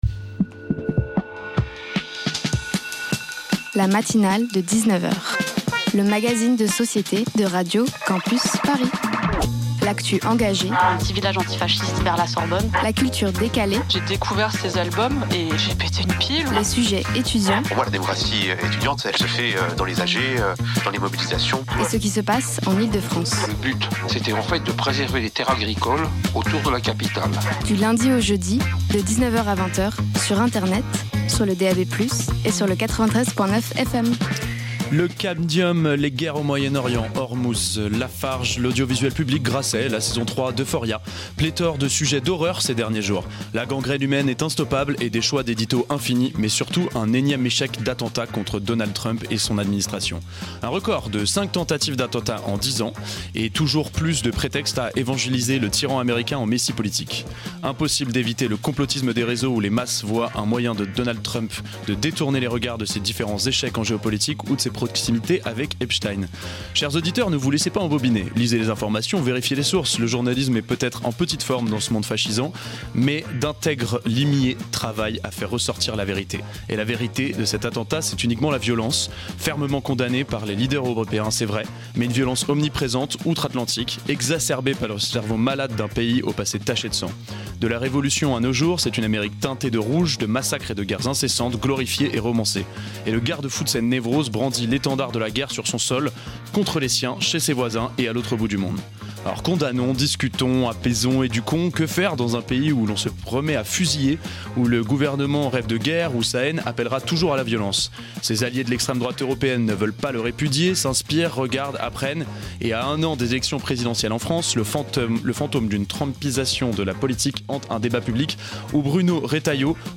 Partager Type Magazine Société Culture lundi 27 avril 2026 Lire Pause Télécharger Ce soir